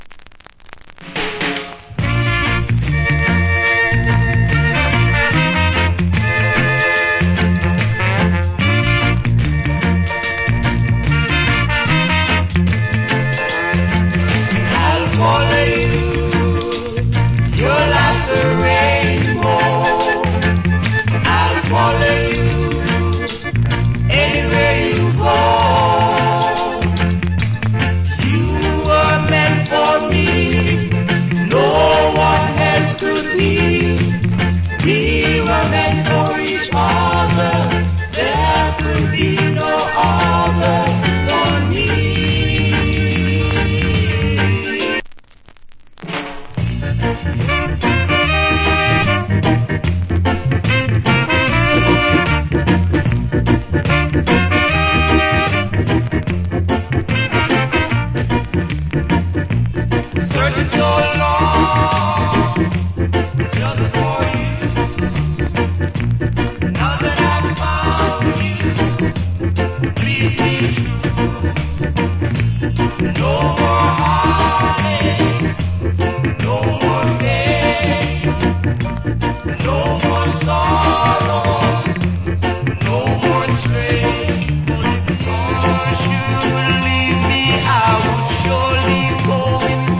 Old Hits Rock Steady.